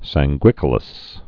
(săng-gwĭkə-ləs)